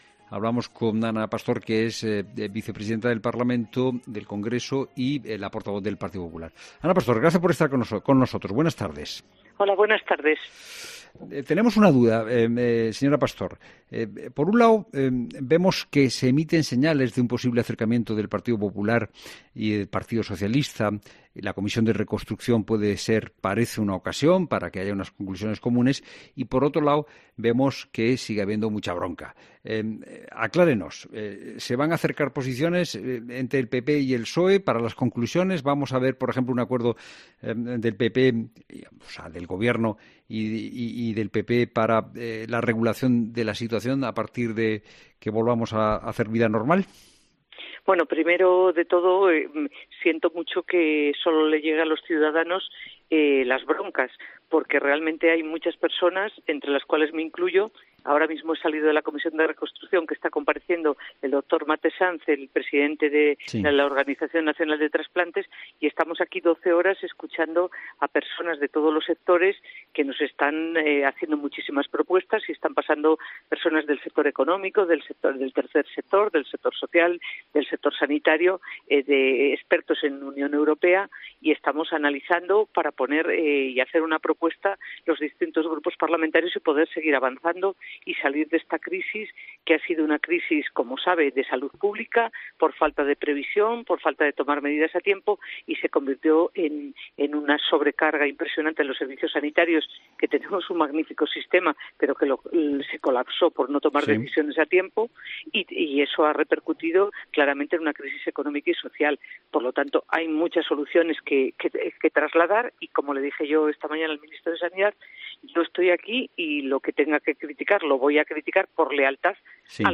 La diputada del PP cuenta en COPE cómo reforzar la sanidad